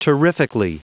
Prononciation du mot terrifically en anglais (fichier audio)
Prononciation du mot : terrifically